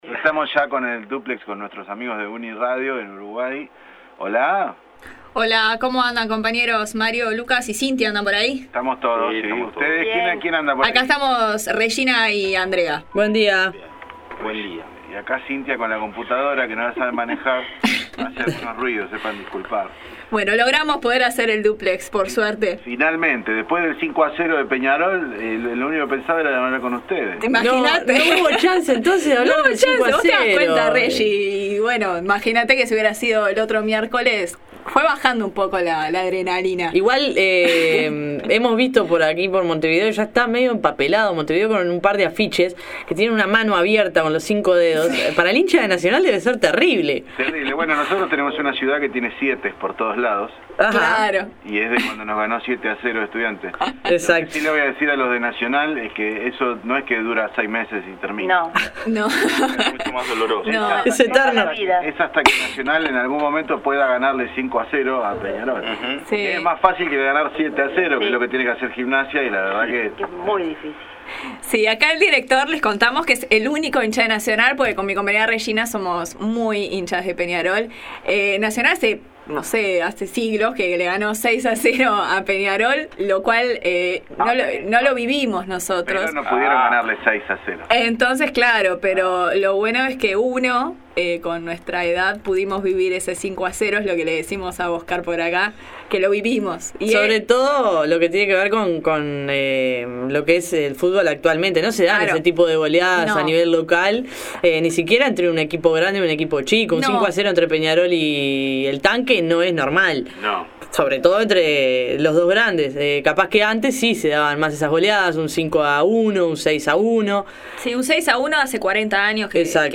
Un nuevo dúplex con los colegas del programa No Se Sabe de la Radio Universidad Nacional de La Plata donde compartimos las noticias de las dos orillas. Este jueves comentamos sobre el contenido del decreto reglamentario de ley de mercado regulado del cannabis, noticia destacada de nuestro país, y sobre la creación del Ministerio de Cultura, el primero en la historia Argentina, y el nombramiento como titular de esa cartera a la cantante Teresa Parodi.